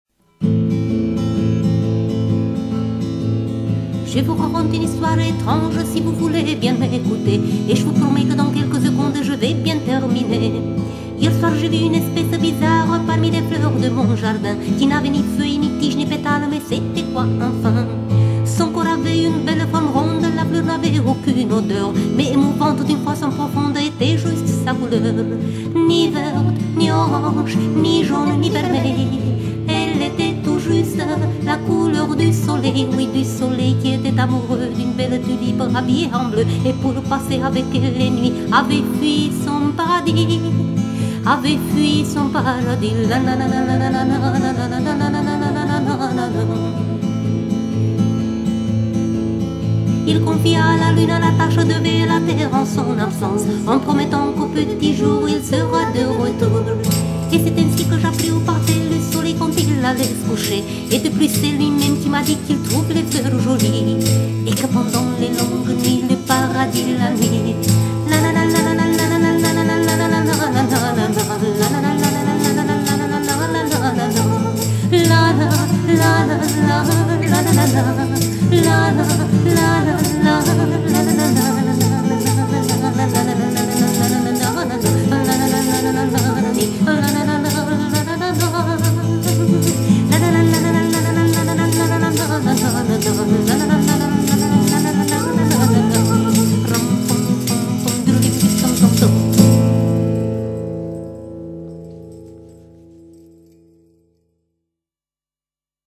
musique, paroles, voix et guitare